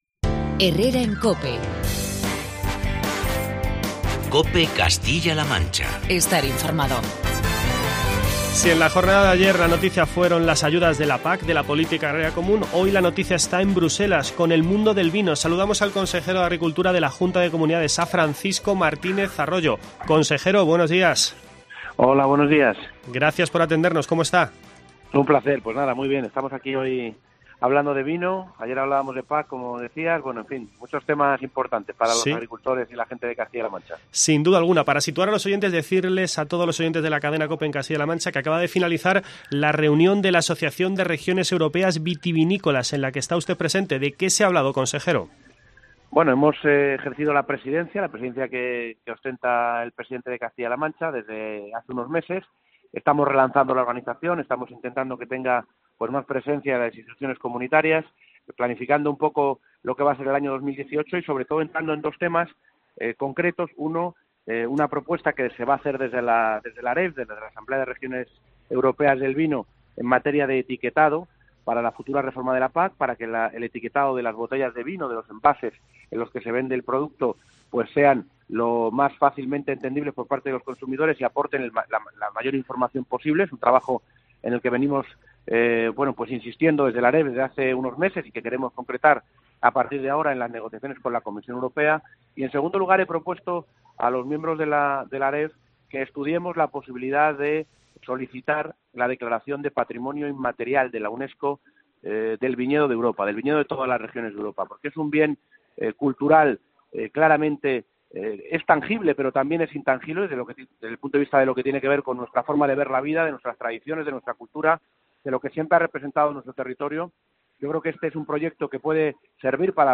Entrevista con Francisco Martínez Arroyo
Conversamos en "Herrera en COPE Castilla-La Mancha" con el consejero de Agricultura, Medio Ambiente y Desarrollo Rural.